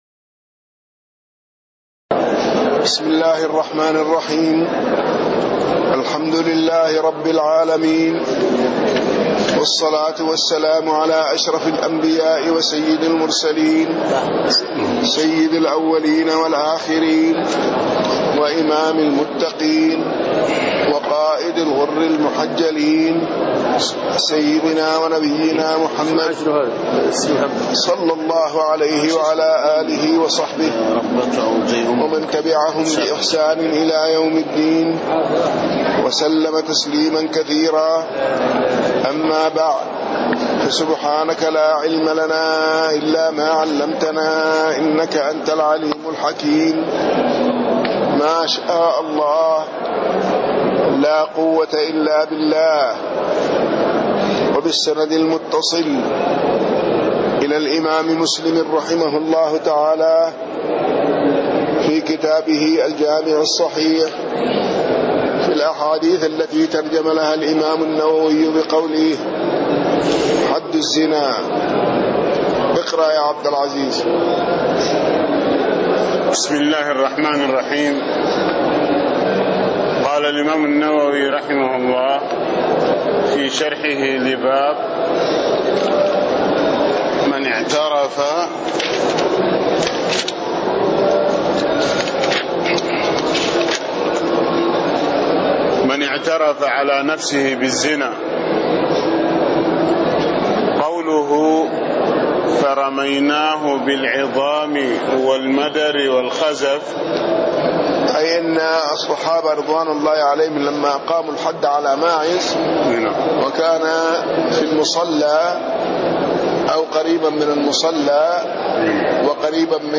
تاريخ النشر ١ رجب ١٤٣٥ هـ المكان: المسجد النبوي الشيخ